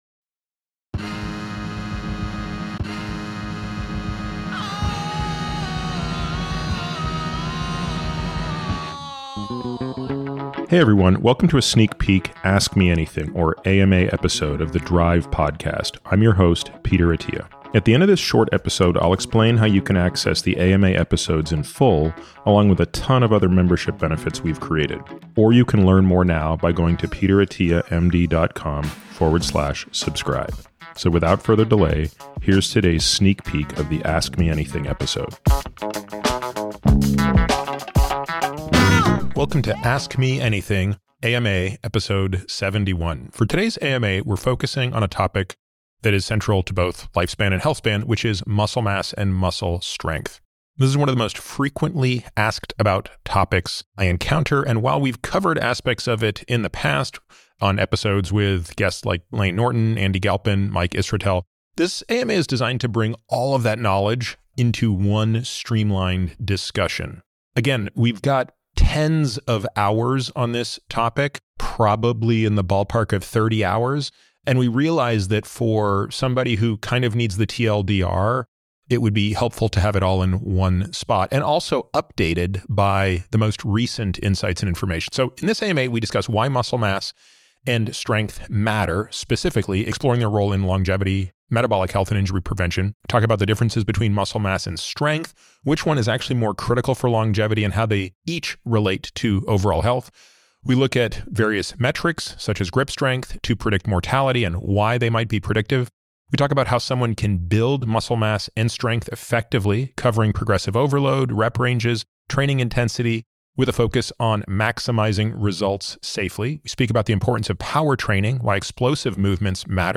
If you’re not a subscriber and listening on a podcast player, you’ll only be able to hear a preview of the AMA.